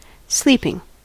Ääntäminen
Ääntäminen US Tuntematon aksentti: IPA : /ˈsliːpɪŋ/ Haettu sana löytyi näillä lähdekielillä: englanti Käännös Substantiivit 1. nukkuminen 2. makuu Adjektiivit 3. nukkuva Sleeping on sanan sleep partisiipin preesens.